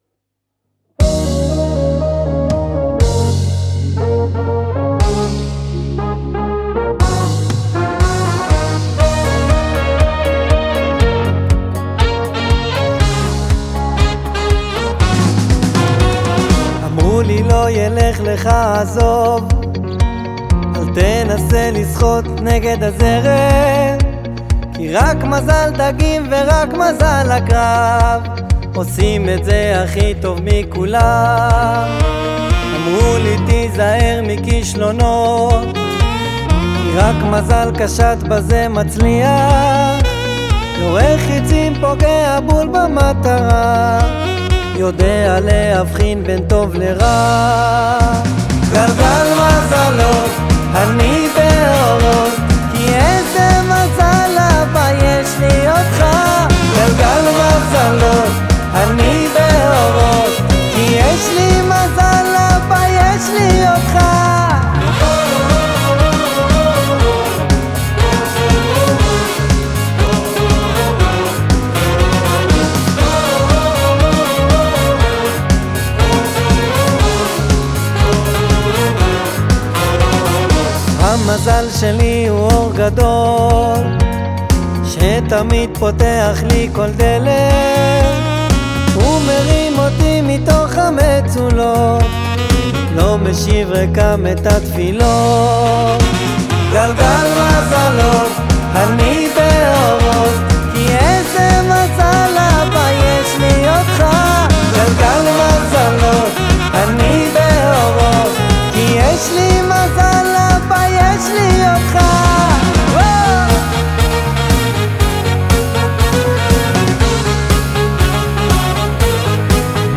בעיבוד אנרגטי שמשלב חוויית שמחה ואמונה עמוקה